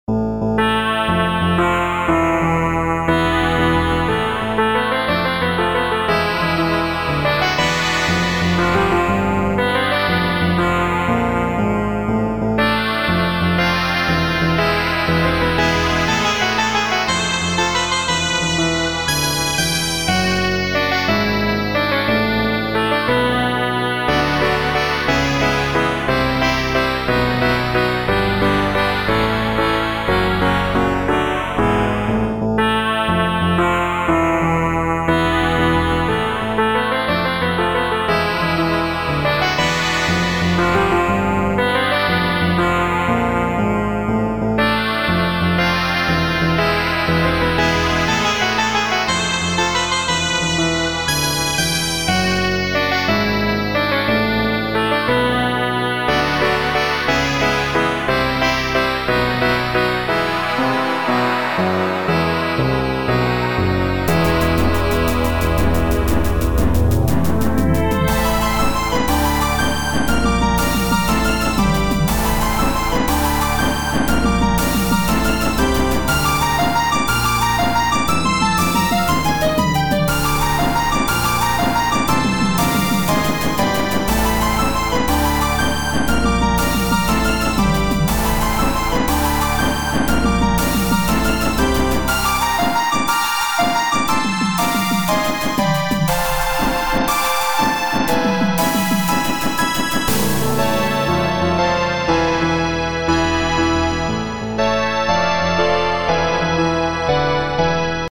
This piece is unfinished, but demonstrates my attempt to produce a strong "twangy" guitar sound . . . It does loop nicely, with a player that loops precisely (no pause).